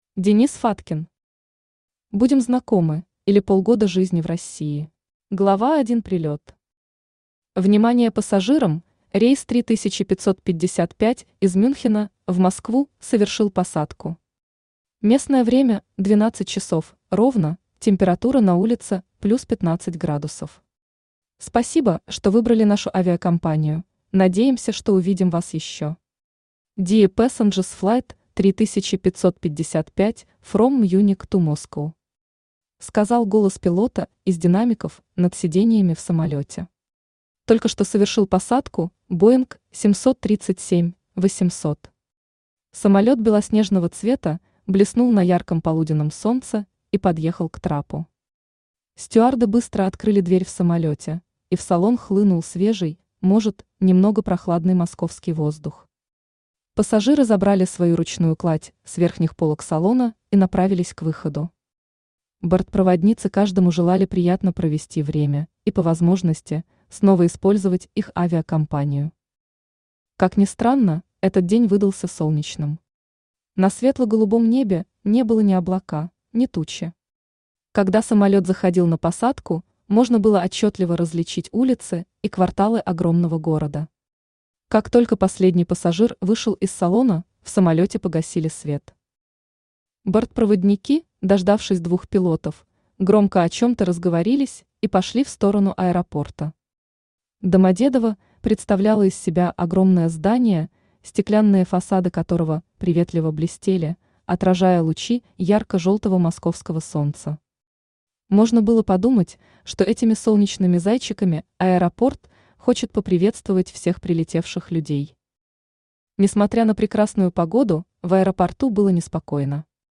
Аудиокнига Будем Знакомы, или полгода жизни в России | Библиотека аудиокниг
Aудиокнига Будем Знакомы, или полгода жизни в России Автор Денис Сергеевич Фаткин Читает аудиокнигу Авточтец ЛитРес.